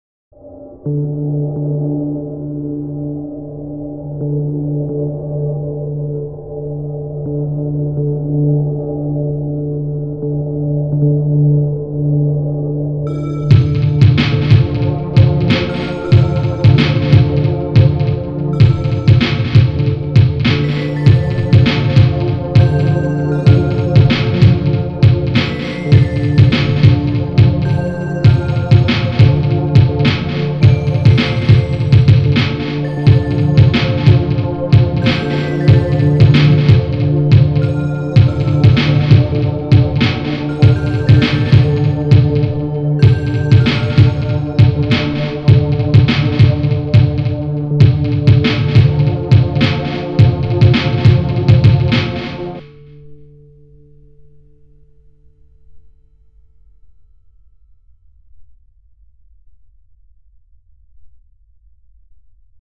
I created this atmospheric layer to blend in with the fog. I then added this bell and the rusty echoing drums.